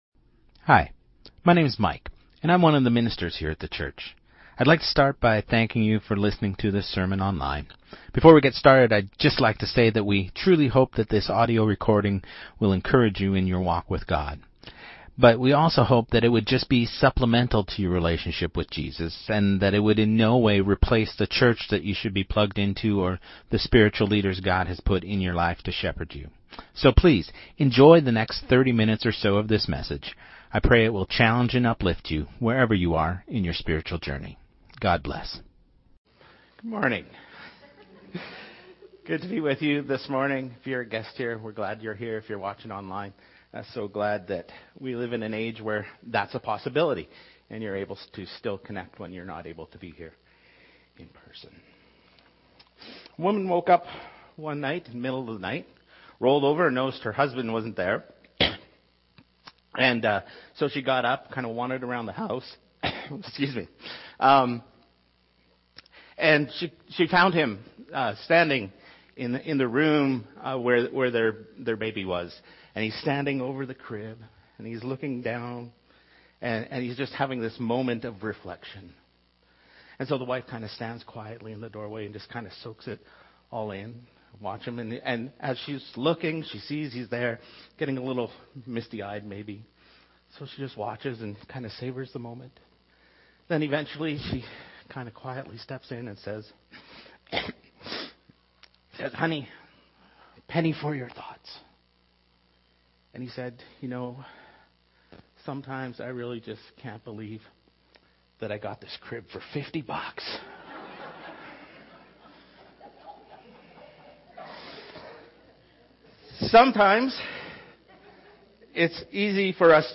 Sermon2026-03-08